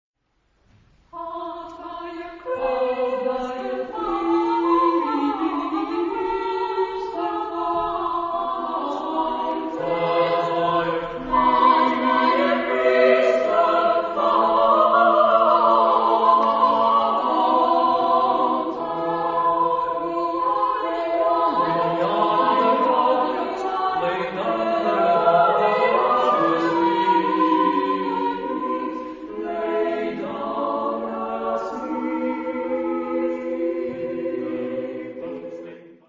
Genre-Stil-Form: Madrigal ; weltlich ; Ode ; Elisabethanische Musik ; Renaissance
Charakter des Stückes: schnell
Chorgattung: SSATTB  (6 gemischter Chor Stimmen )
Tonart(en): G (tonales Zentrum um)